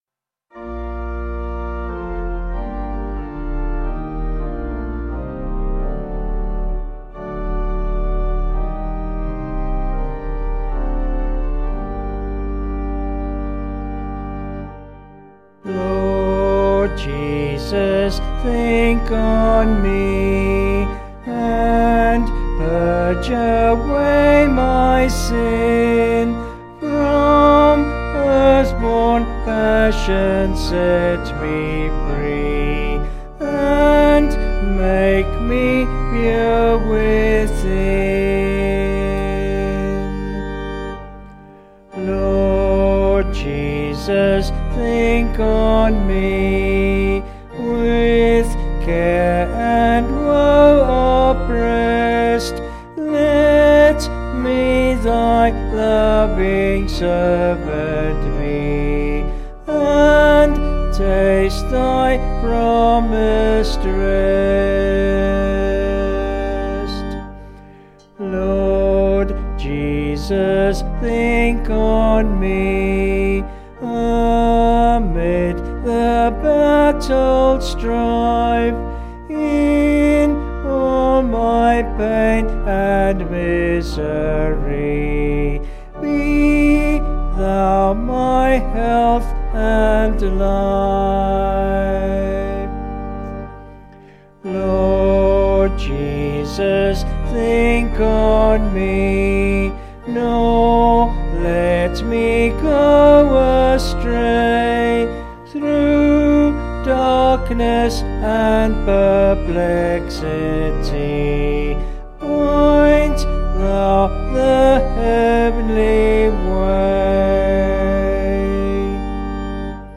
Vocals and Organ   264.2kb Sung Lyrics